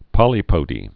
(pŏlē-pōdē)